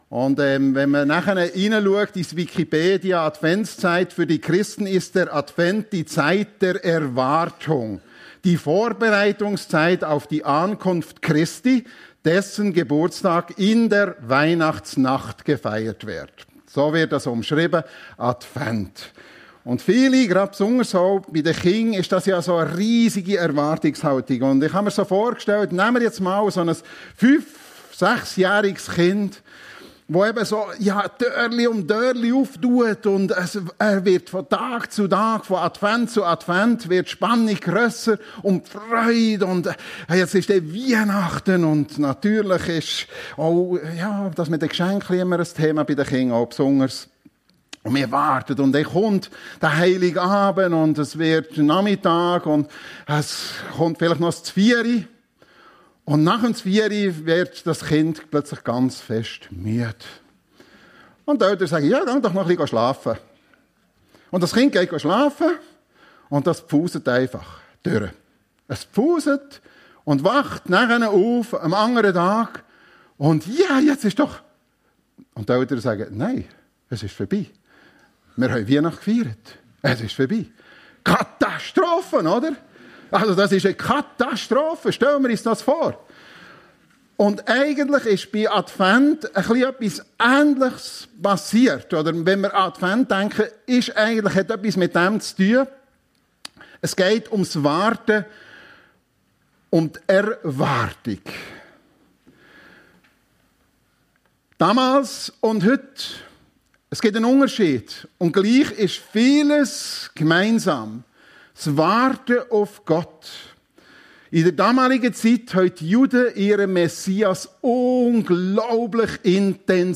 Advent - Warten auf Gott ~ FEG Sumiswald - Predigten Podcast